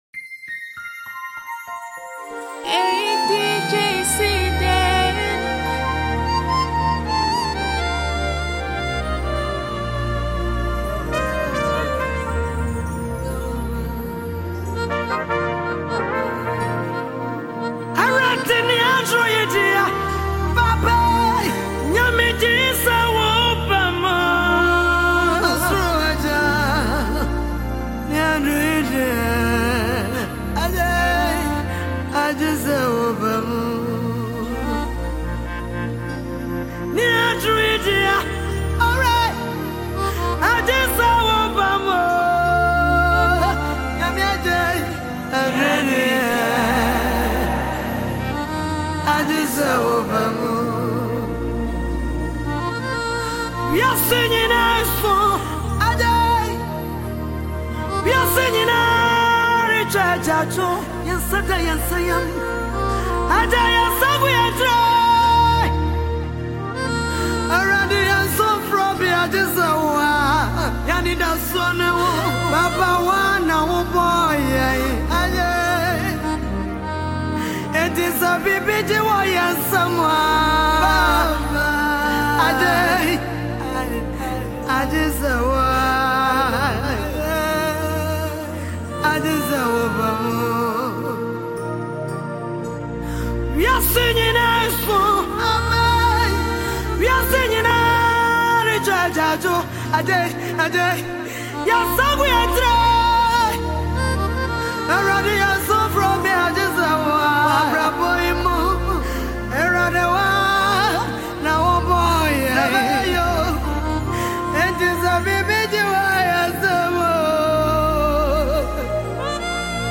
Ghanaian disc jockey